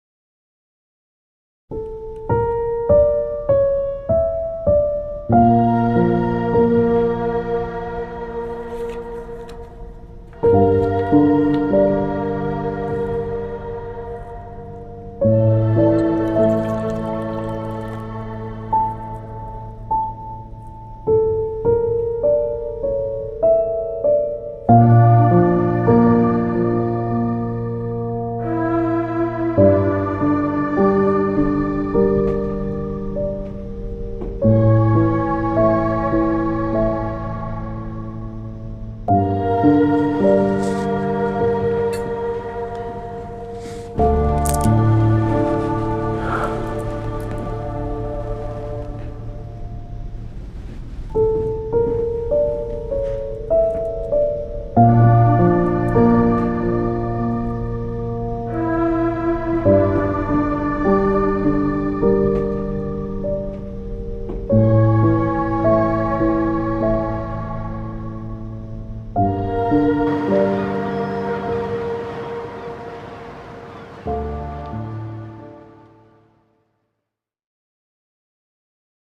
tema dizi müziği, duygusal hüzünlü üzgün fon müzik.